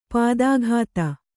♪ pādāghāta